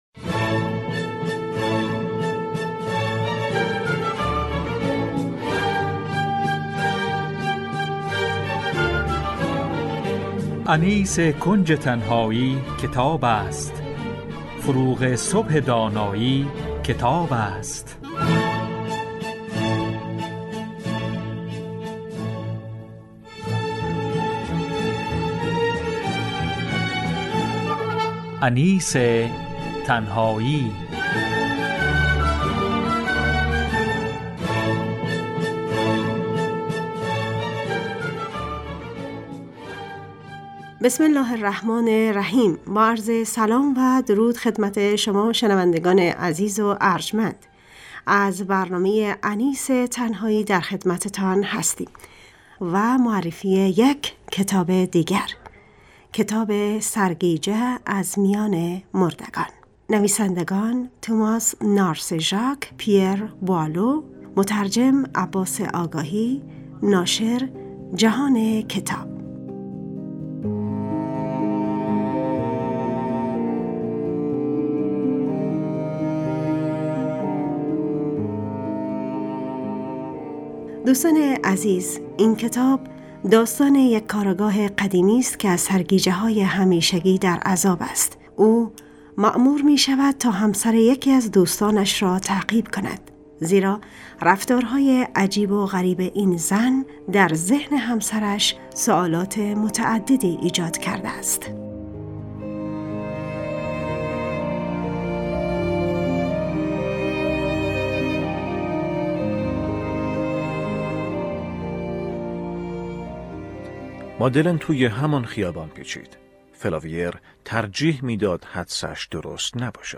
معرفی کتاب